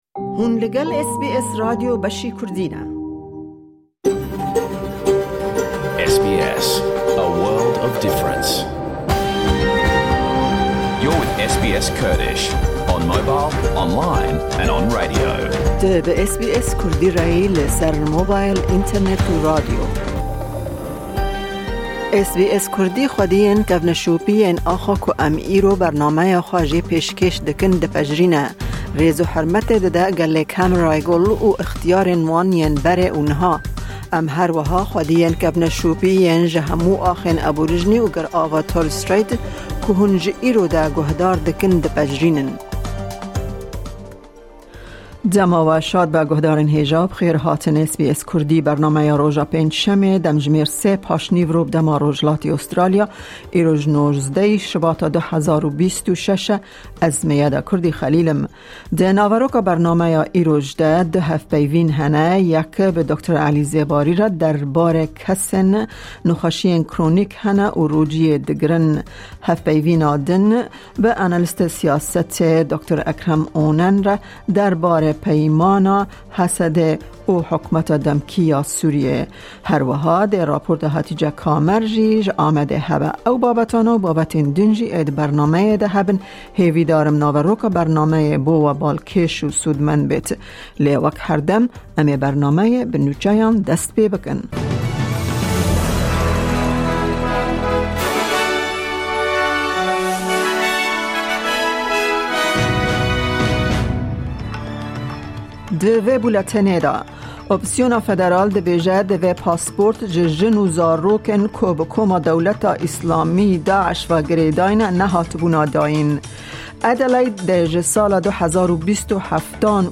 Nûçe, raportên ji Hewlêr û Amedê, hevpeyvîn û gelek babetên di yên cur bi cur di naveroka bernameyê de tên dîtin.